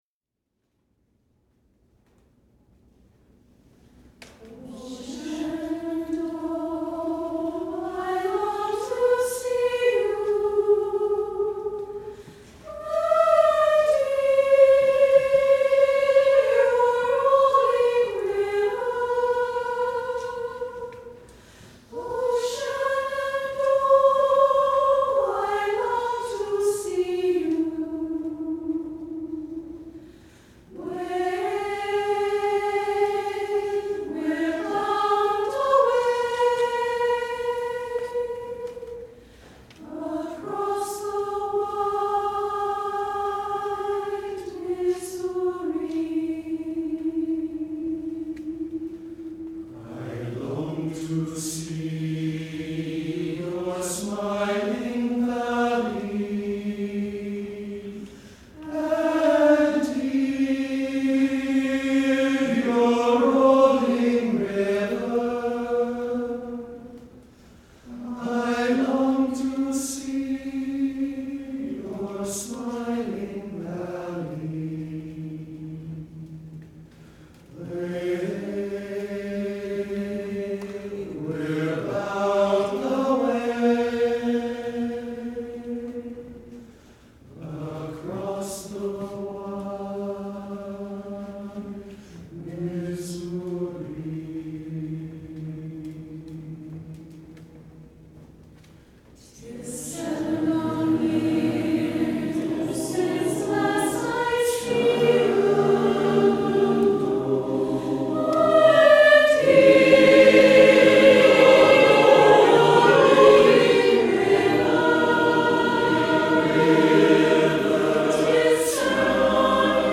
Below you will find select audio and video from our past performances for your listening and viewing enjoyment.
From our March 30, 2014 concert: